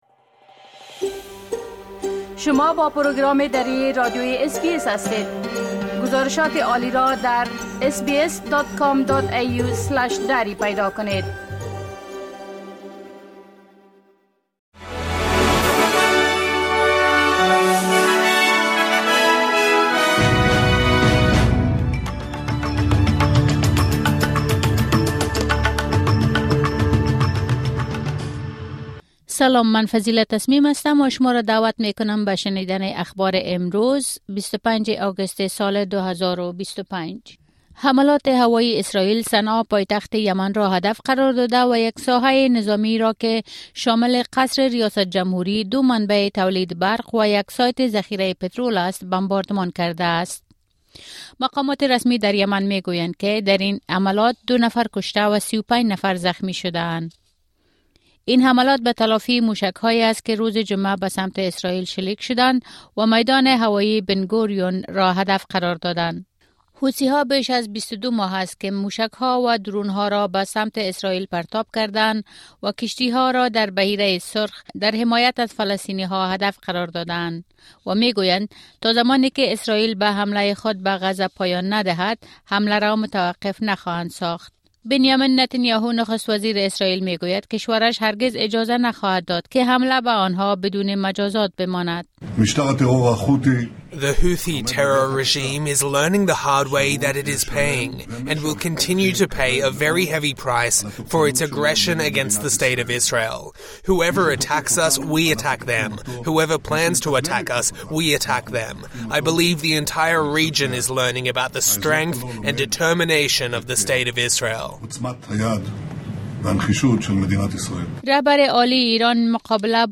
خلاصه مهمترين خبرهای روز از بخش درى راديوى اس‌بى‌اس ۲۵ اگست